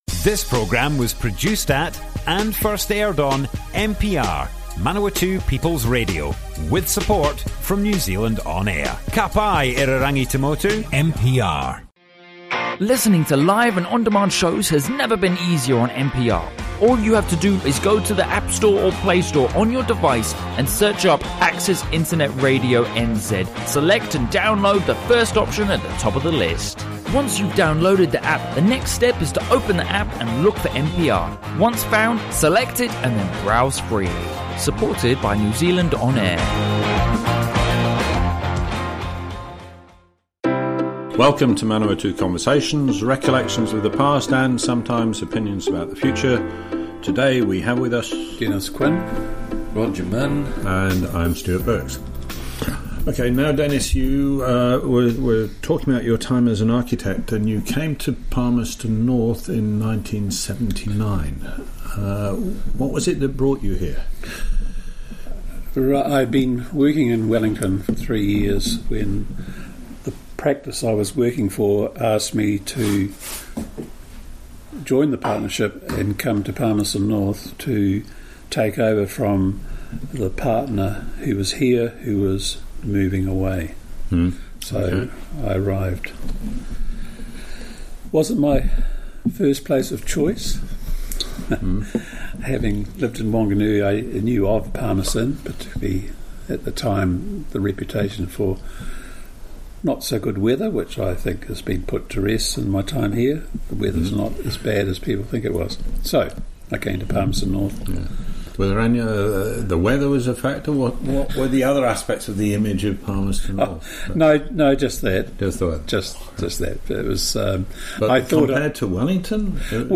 Broadcast on Manawatu People's Radio 27 November 2018.